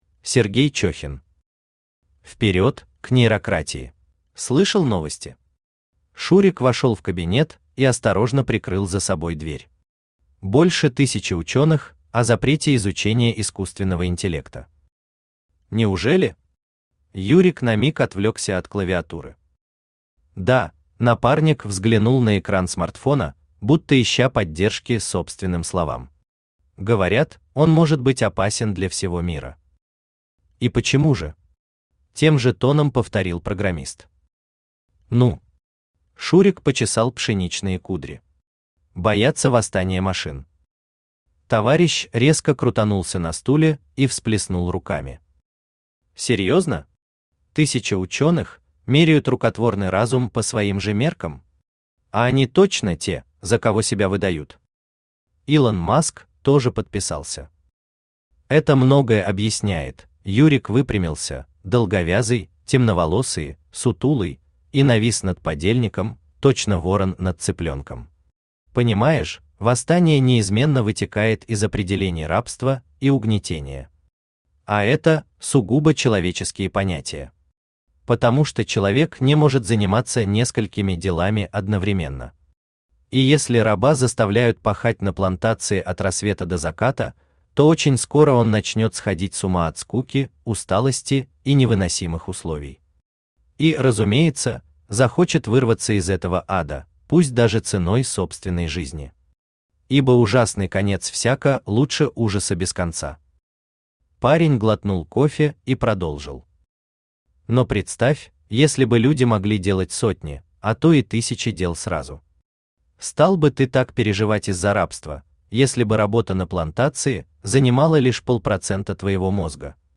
Аудиокнига Вперед, к нейрократии!
Автор Сергей Николаевич Чехин Читает аудиокнигу Авточтец ЛитРес.